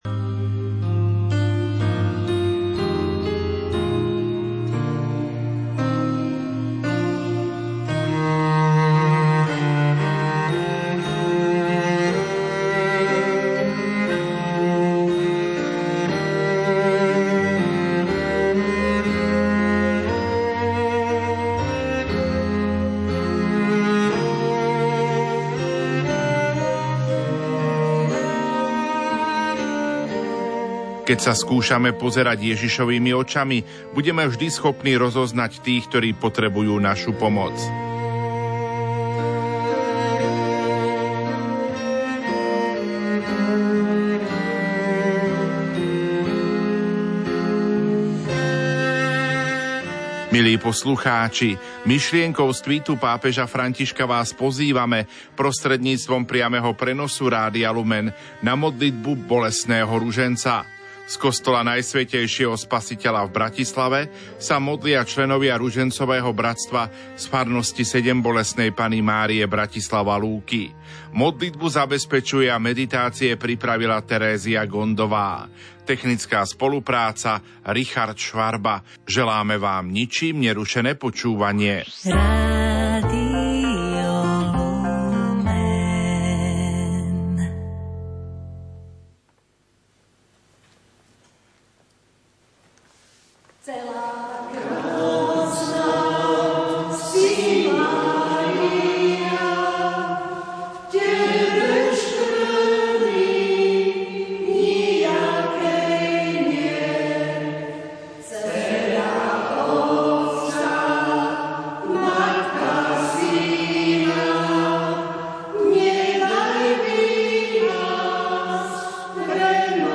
modlitba bolestného ruženca z Jezuitského kostola Najsvätejšieho Spasiteľa v Bratislave modlia sa členovia ružencového bratstva z farnosti Sedembolestnej Panny Márie Bratislava-Lúky